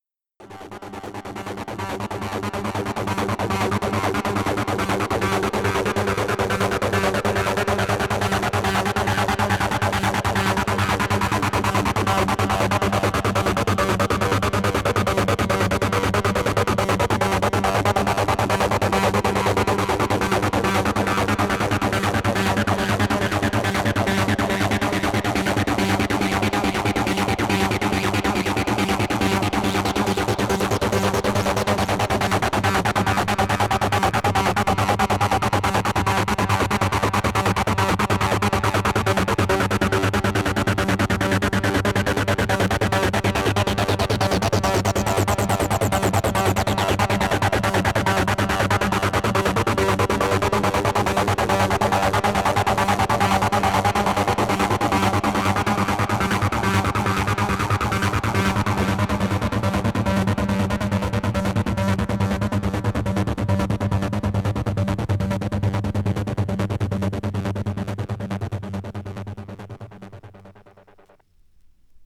Hardtek/Tekno